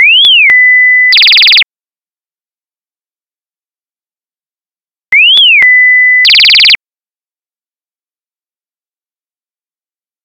Robo-Towhee